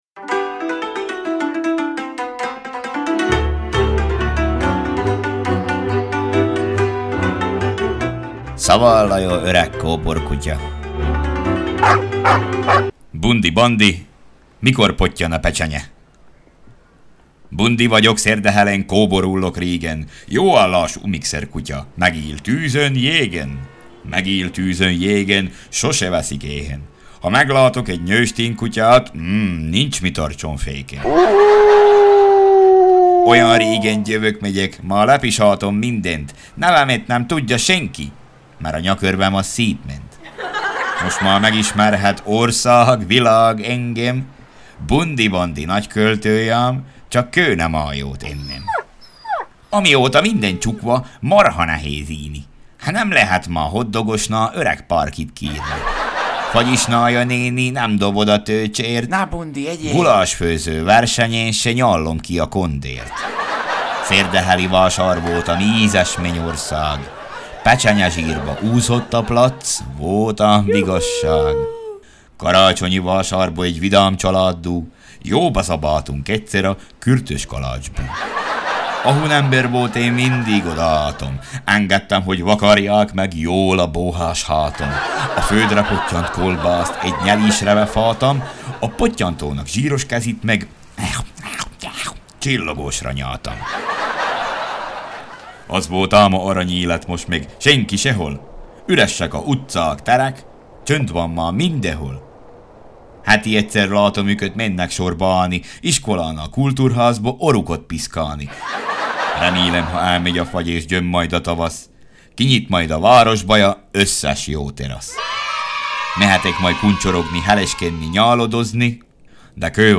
Zene: Pósfa zenekar - Szól a fülemüle Baha Men - Who let the dogs out